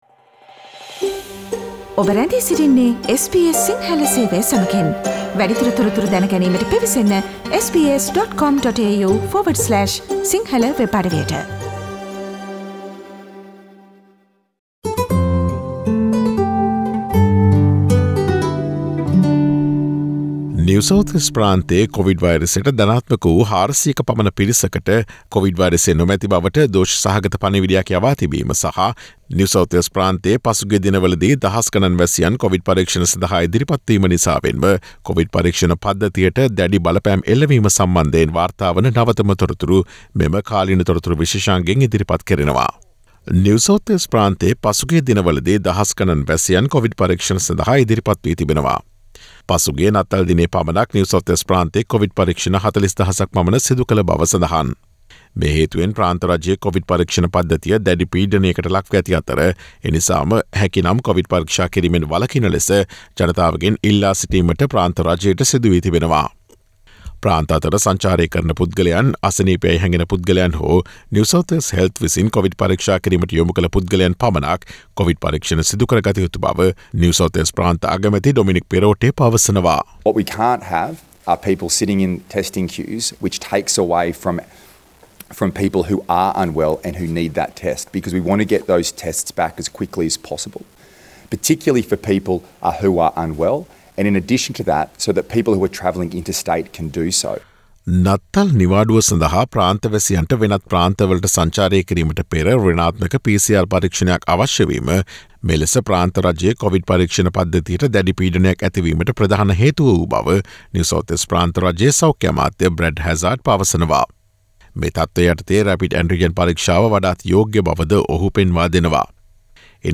නිව් සවුත් වේල්ස් ප්‍රාන්තයේ නවතම කොවිඩ් තතු විත්ති රැගත් දෙසැම්බර් 27 වන දා සඳුදා ප්‍රචාරය වූ SBS සිංහල සේවයේ කාලීන තොරතුරු විශේෂාංගයට සවන්දෙන්න.